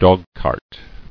[dog·cart]